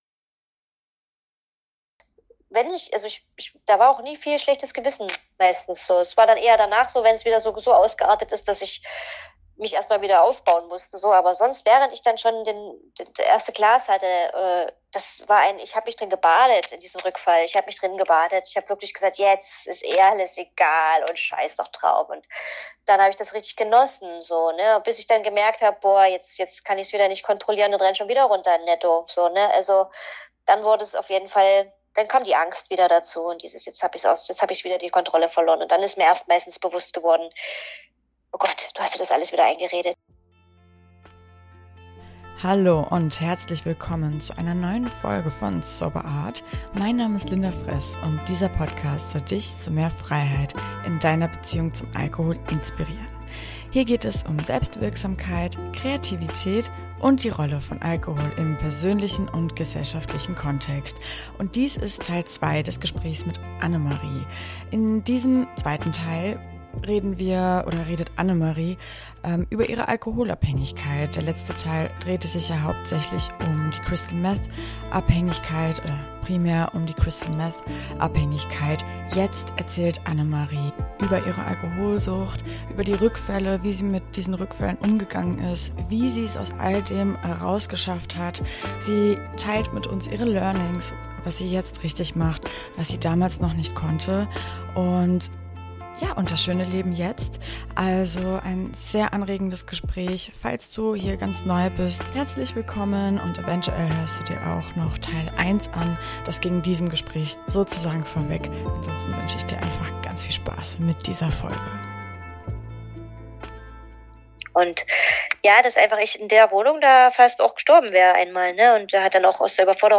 Gesprächs